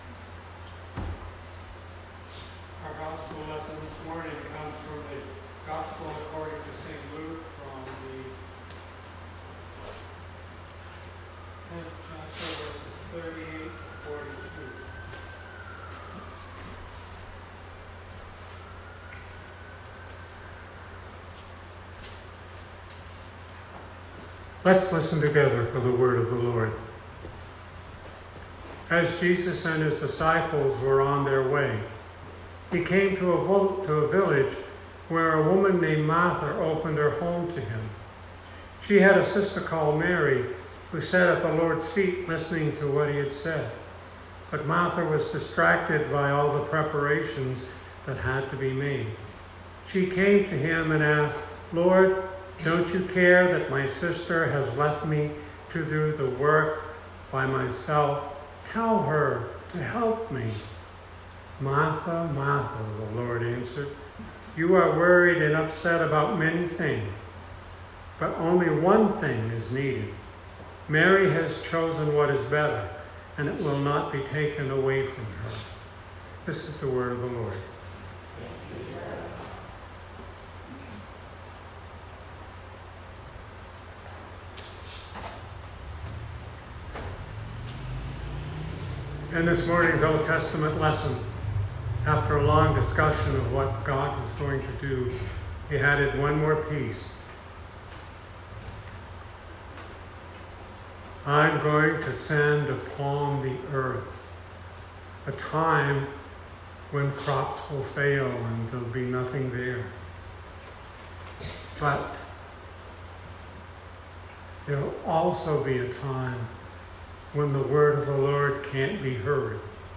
Sermon 2019-07-21